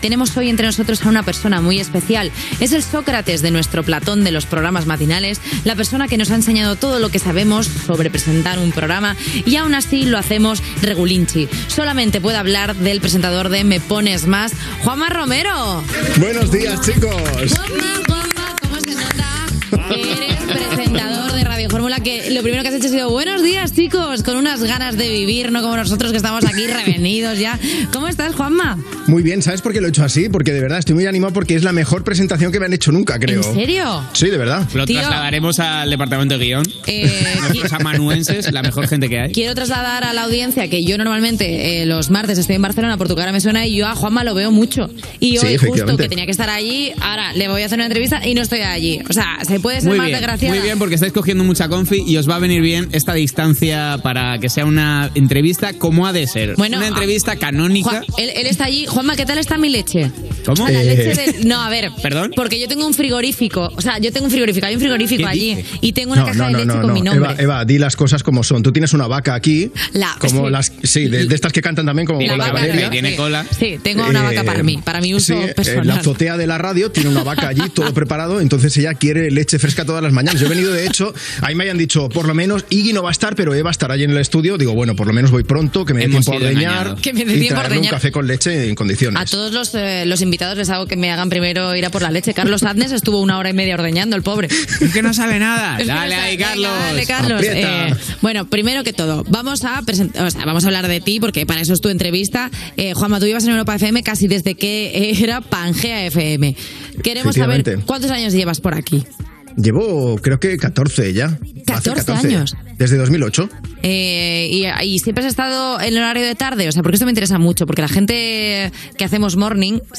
Fragment d'una entrevista
Gènere radiofònic Entreteniment